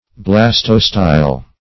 Search Result for " blastostyle" : The Collaborative International Dictionary of English v.0.48: Blastostyle \Blas"to*style\, n. [Gr. blasto`s sprout, bud + ? a pillar.]